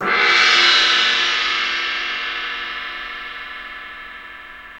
Index of /90_sSampleCDs/Roland L-CDX-01/CYM_FX Cymbals 1/CYM_Cymbal FX
CYM DRGCY0FR.wav